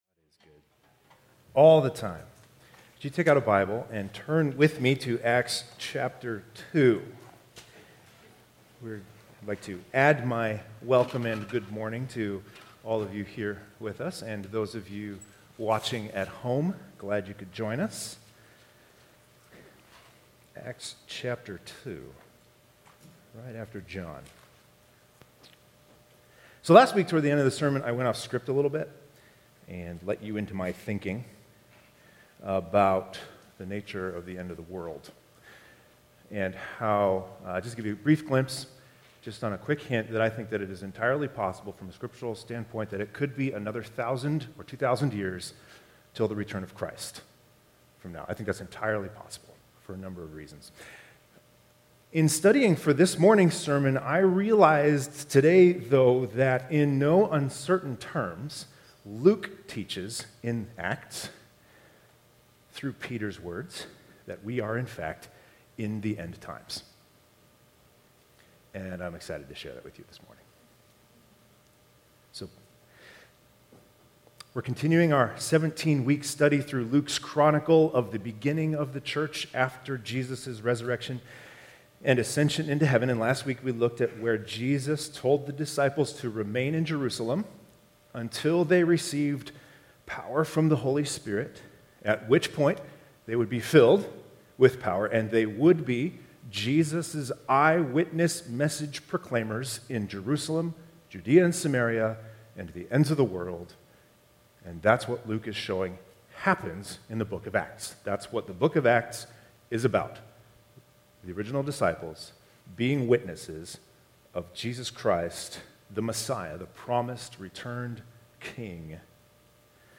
Sermon manuscript (PDF) Download Please turn with me to the book of Acts, and chapter 2.